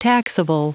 Prononciation du mot taxable en anglais (fichier audio)